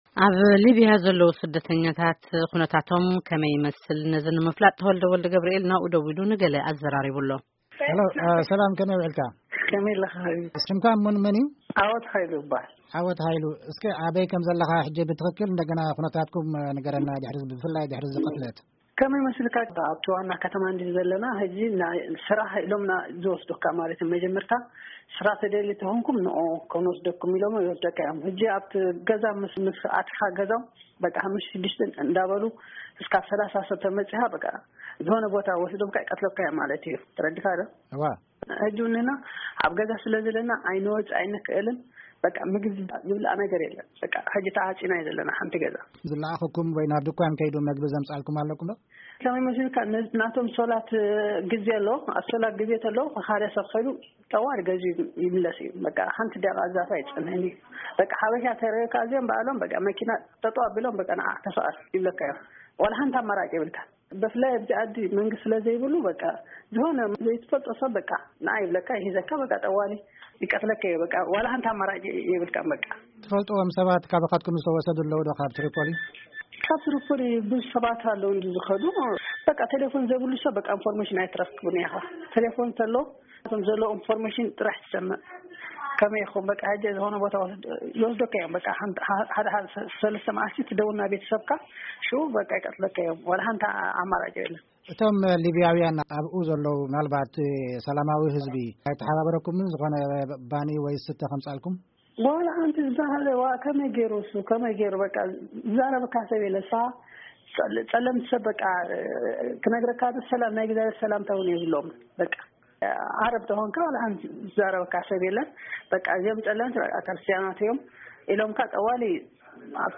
VOA Interview -Libya